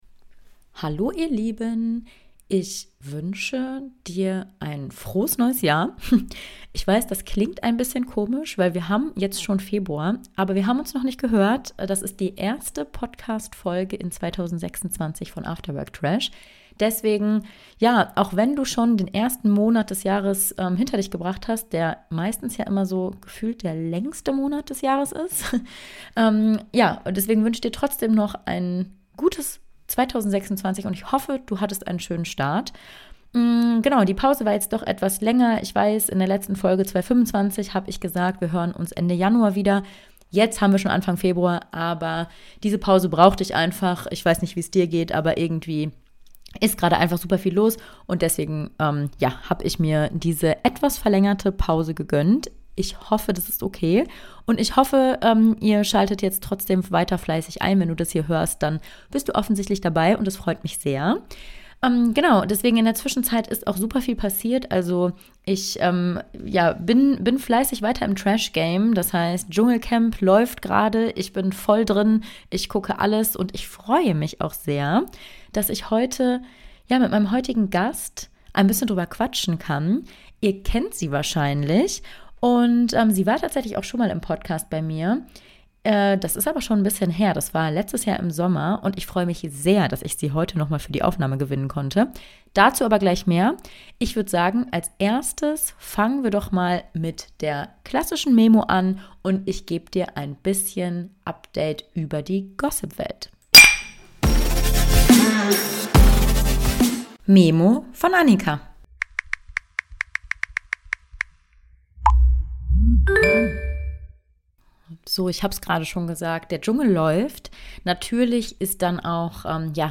Intro: Music